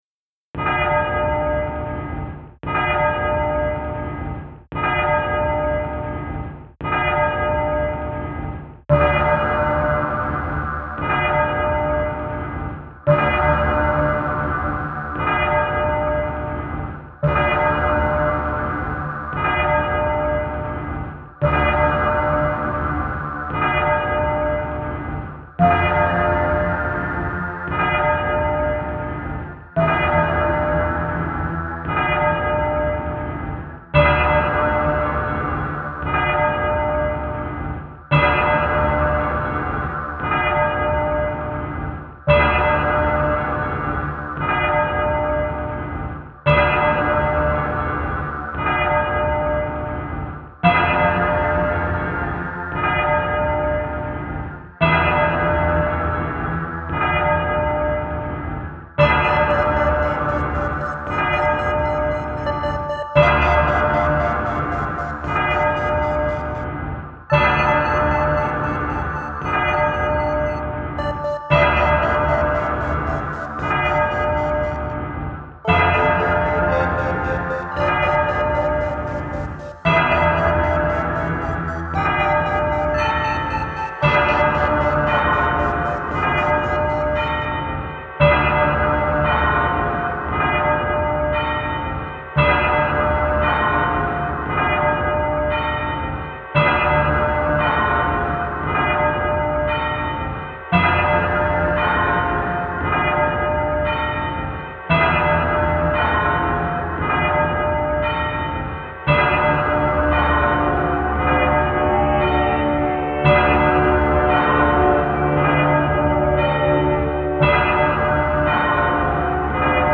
Piazza della Biade, Vicenza reimagined